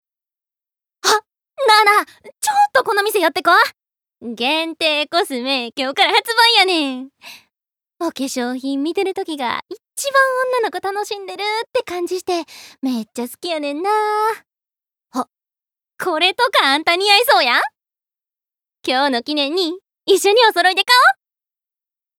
Voice Sample
セリフ４